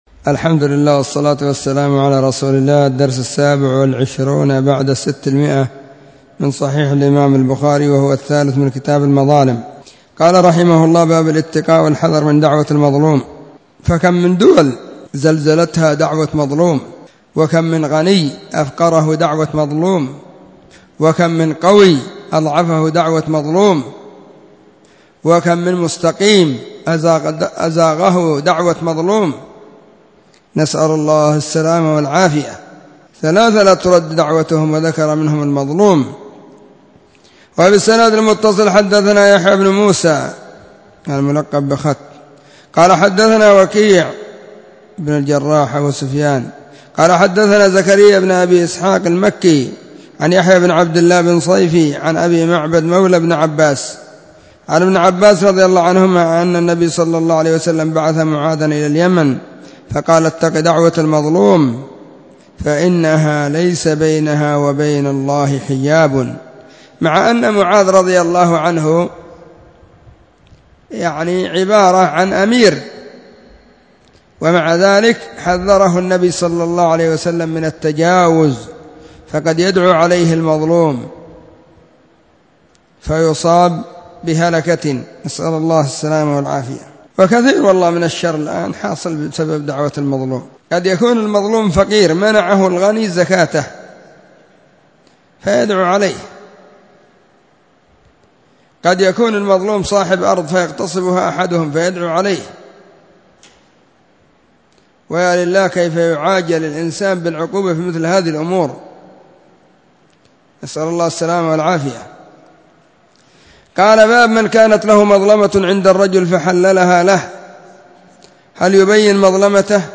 🕐 [بين مغرب وعشاء – الدرس الثاني]
🕐 [بين مغرب وعشاء – الدرس الثاني] 📢 مسجد الصحابة – بالغيضة – المهرة، اليمن حرسها الله. 🗓الاحد17 /صفر/ 1442 هجرية 📖صحيح البخاري 627 # كتاب_المظالم-الدرس03 من قوله : باب الاتقاء والحذر من دعوة المظلوم. إلى قوله :باب إثم من ظلم شيء من الارض.